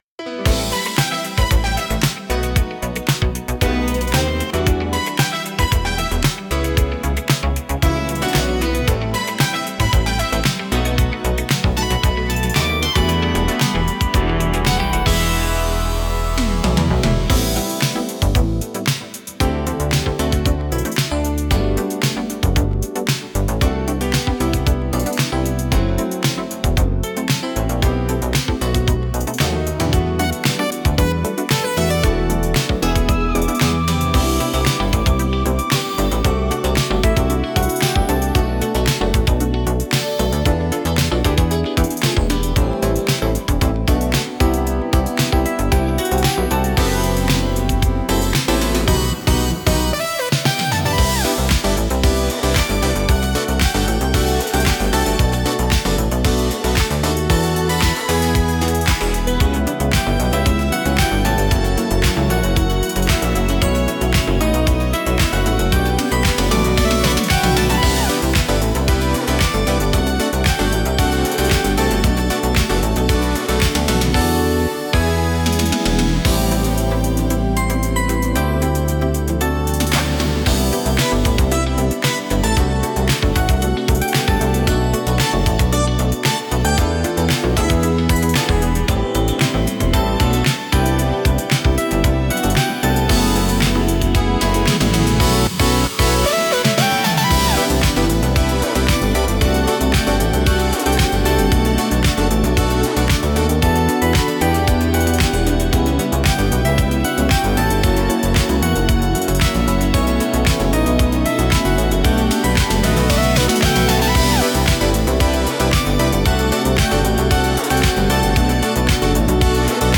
シティポップは、1970～80年代の日本で生まれたポップスの一ジャンルで、都会的で洗練されたサウンドが特徴です。